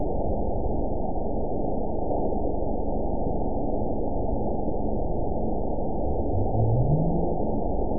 event 922629 date 02/10/25 time 04:54:24 GMT (2 months, 3 weeks ago) score 9.58 location TSS-AB02 detected by nrw target species NRW annotations +NRW Spectrogram: Frequency (kHz) vs. Time (s) audio not available .wav